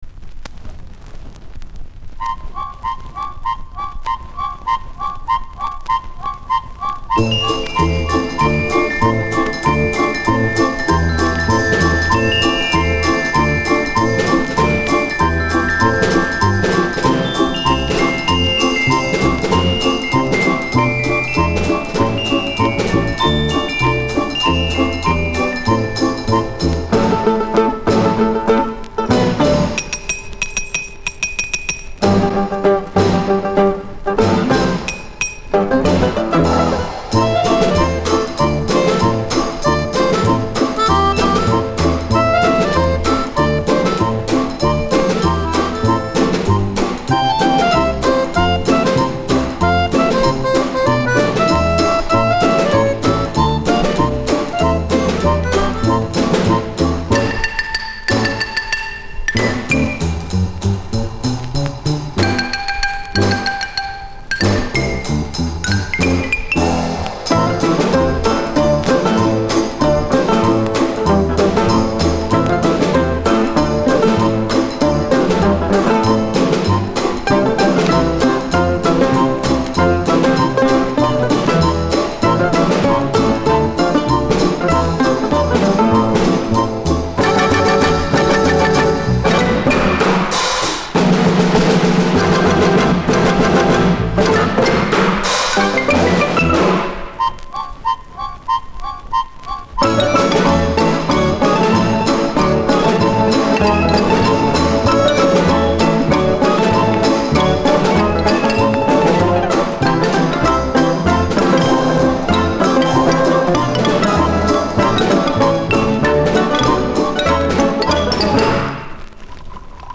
Самовар... солист
Чтобы он пел, мы вставили в него губную гармошку.
джаз-ансамбль